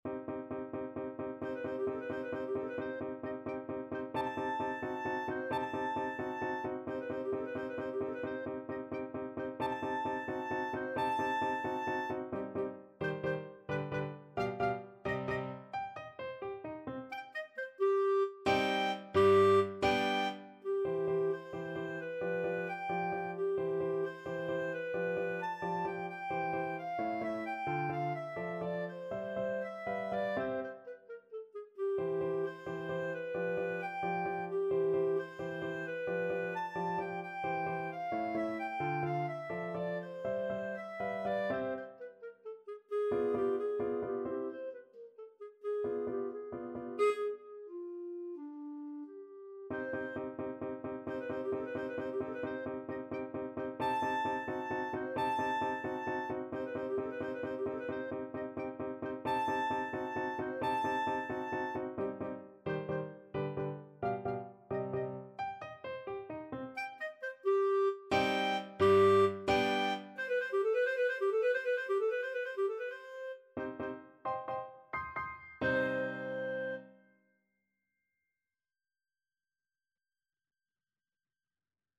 3/8 (View more 3/8 Music)
Classical (View more Classical Clarinet Music)